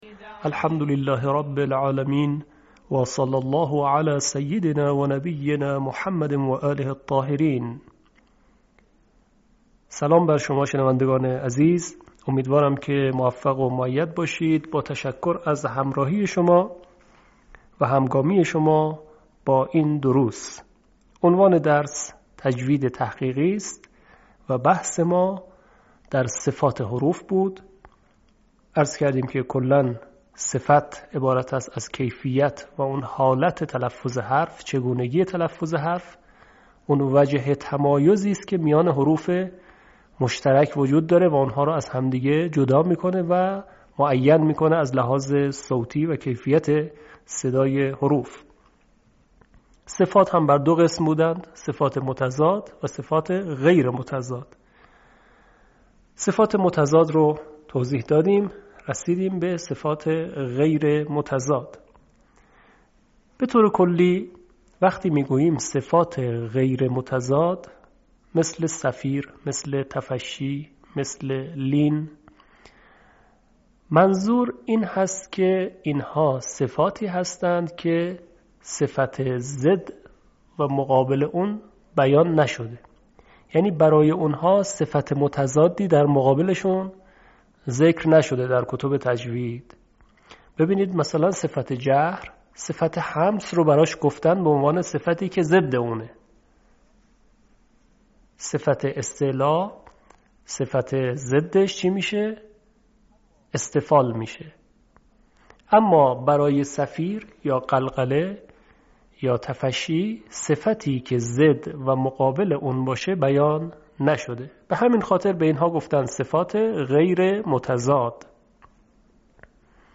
آموزش تجوید تحقیقی